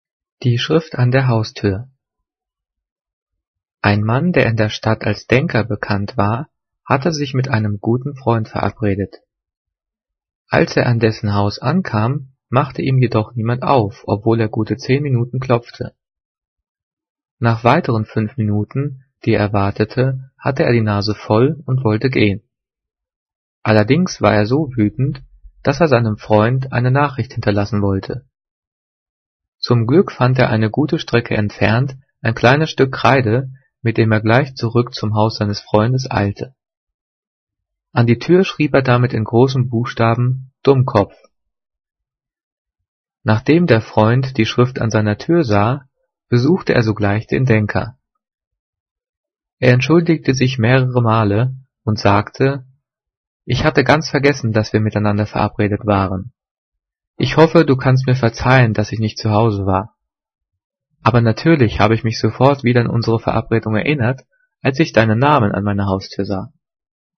Gelesen:
gelesen-die-schrift-an-der-haustuer.mp3